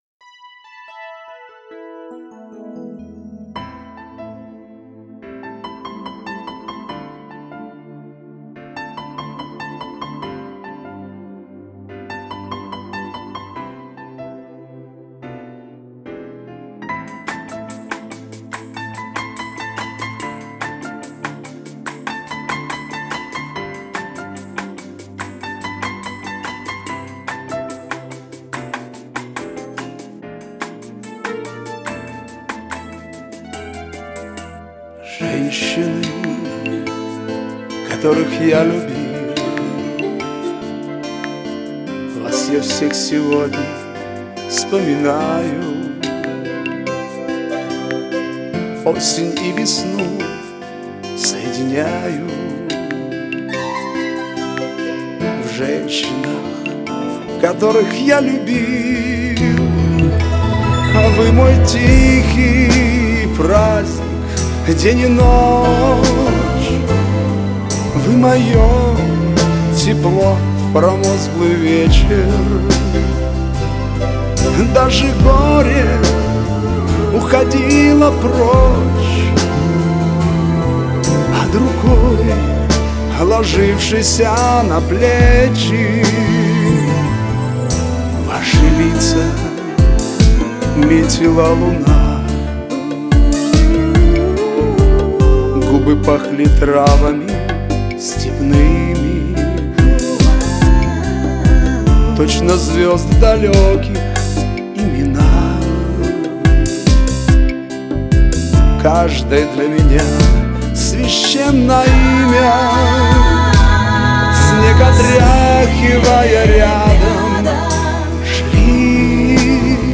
Приятная песня, красивая, лиричная!